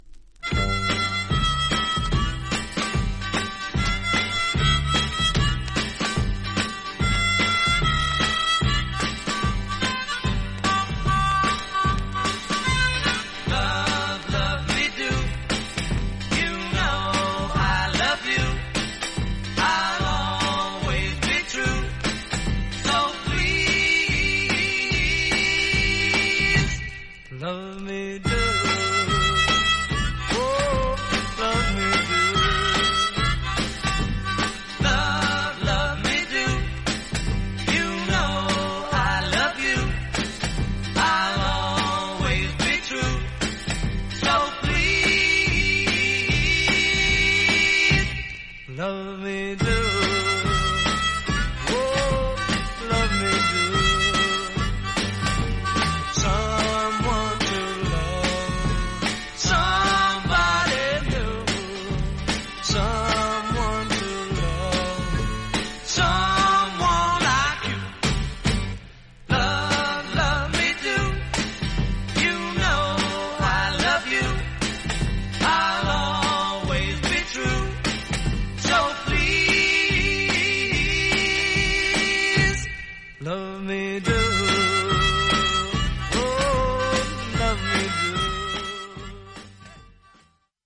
※C①②⑤、D②にプレッシングバブルがあり、そこはぱちノイズが入ります。
実際のレコードからのサンプル↓ 試聴はこちら： サンプル≪mp3≫